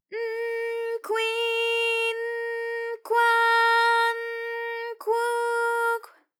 ALYS-DB-001-JPN - First Japanese UTAU vocal library of ALYS.
kw_n_kwi_n_kwa_n_kwu_kw.wav